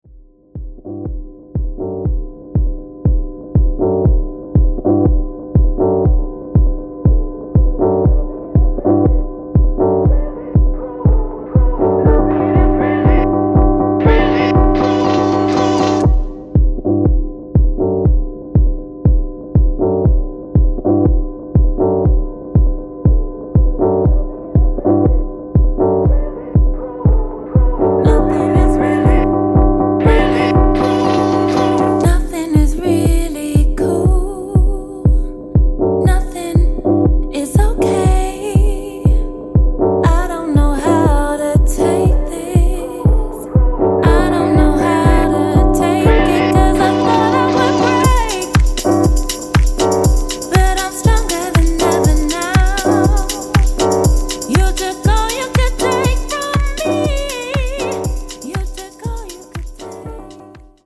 psychedelic rock tinged funky hammond jazz